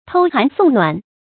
偷寒送暖 tōu hán sòng nuǎn
偷寒送暖发音
成语注音 ㄊㄡ ㄏㄢˊ ㄙㄨㄙˋ ㄋㄨㄢˇ